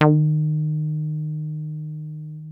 303 D#3 2.wav